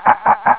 cranking.wav